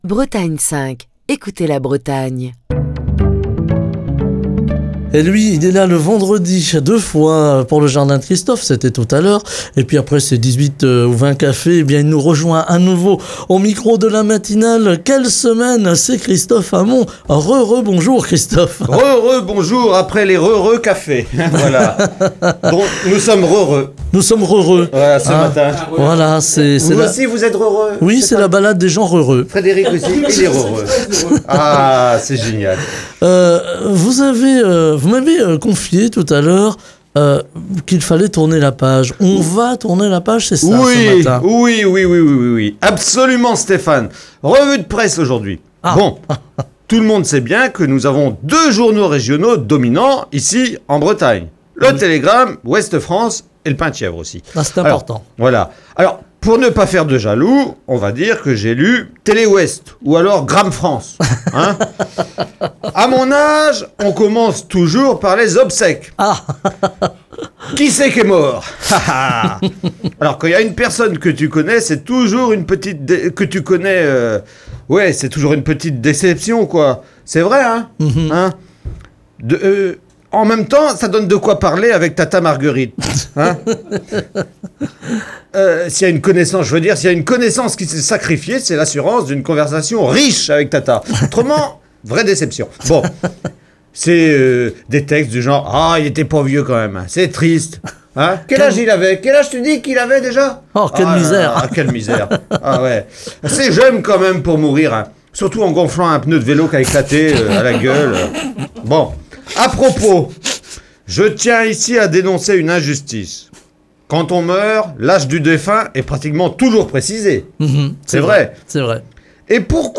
Chronique du 24 mai 2024.